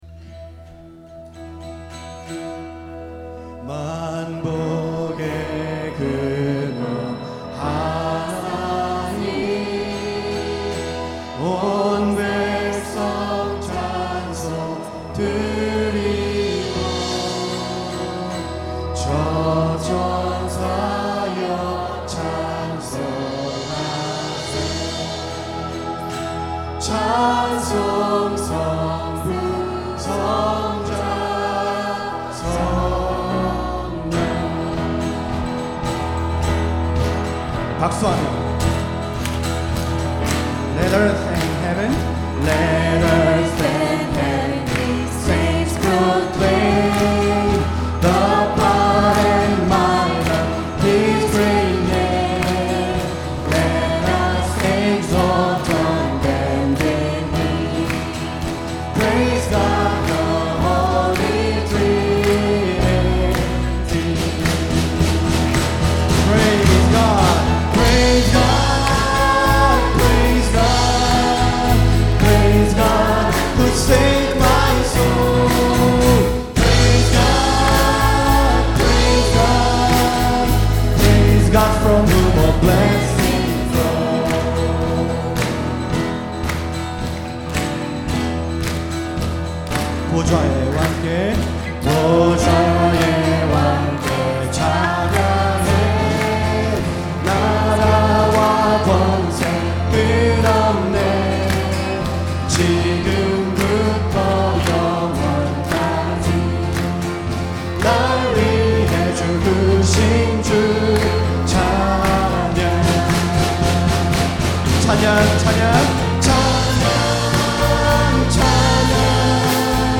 5월 12일 경배찬양
Bansuk Praise Team
Piano
Keyboard
Drums
Bass Guitar
A. Guitar